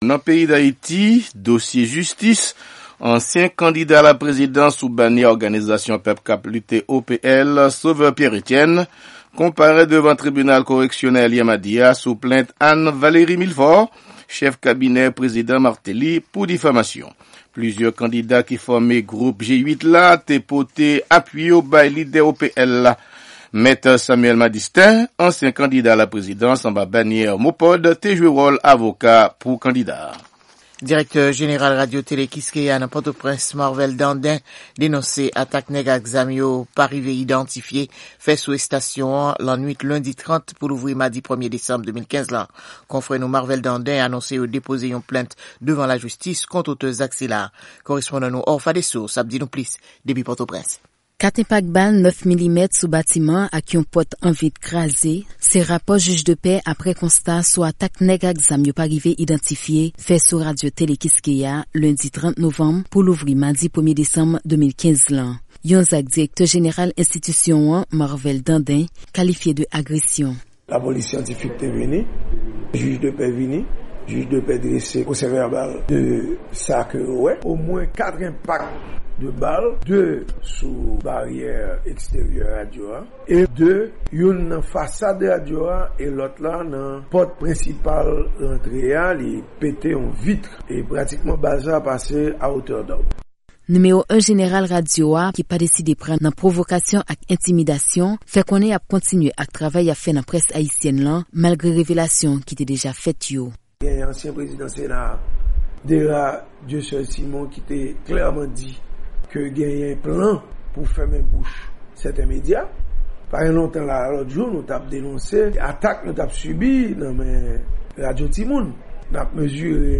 NOUVEL AYITI POU MEKREDI 2 DESANM 2015 LA